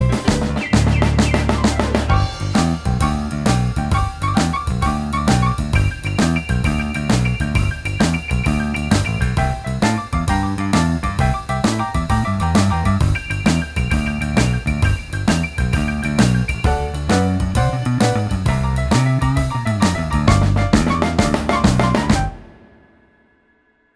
Minus Guitars Rock 'n' Roll 2:44 Buy £1.50